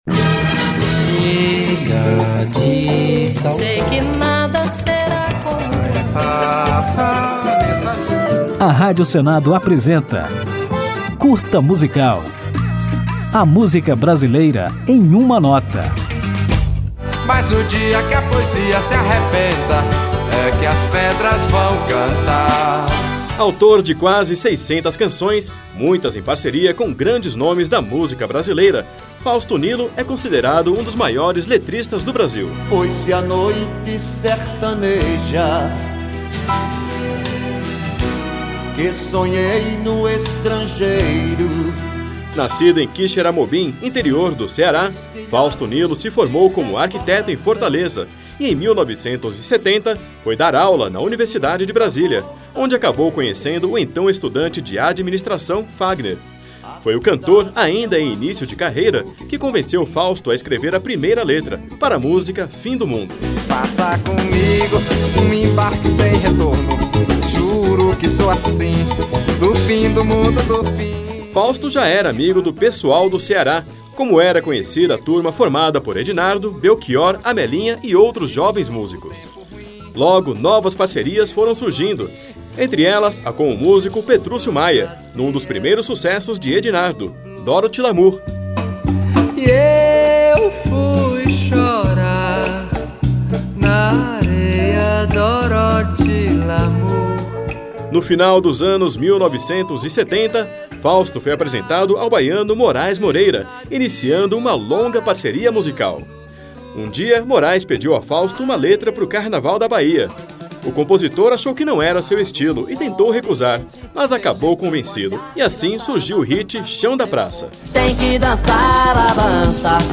Depois de um panorama da trajetória artística de Fauso Nilo, vamos ouvir uma de suas letras mais célebres, a da música Eu Também Quero Beijar, lançada em 1981 pelo parceiro Pepeu Gomes.